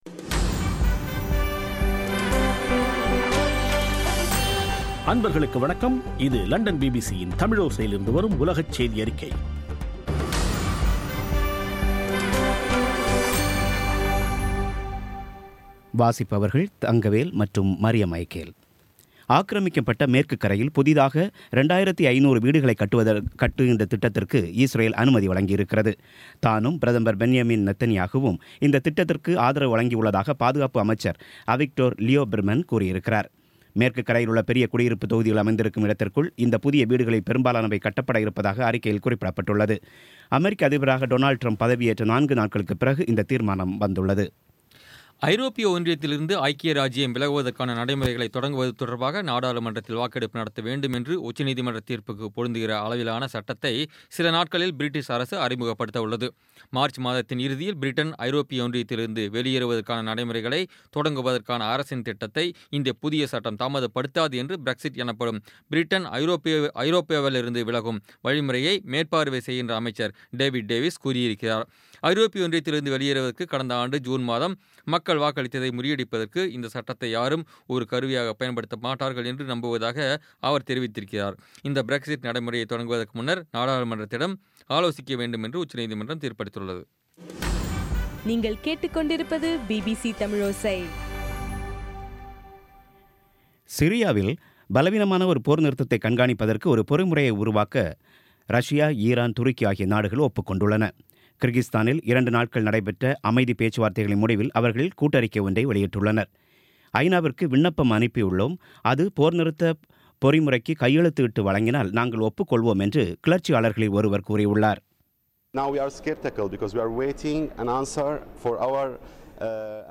பிபிசி தமிழோசை செய்தியறிக்கை (24/01/2017)